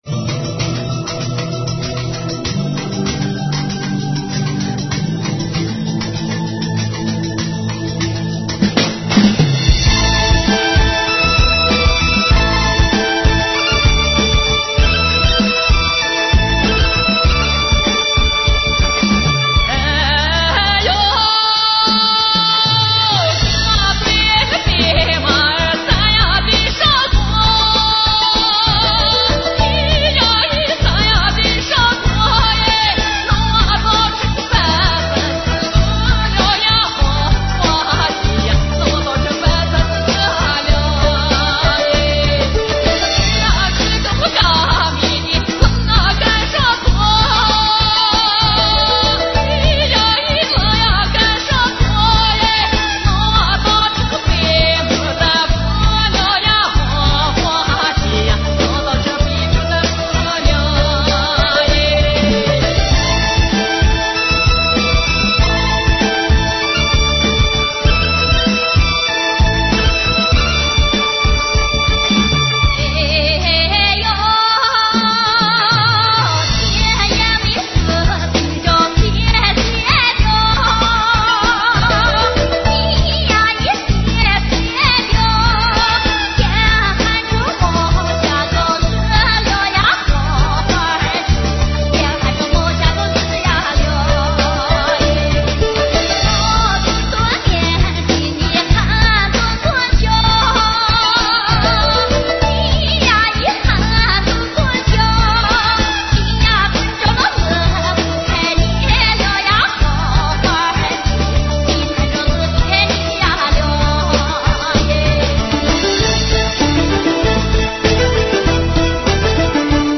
首页 > 图文板块 > 临夏花儿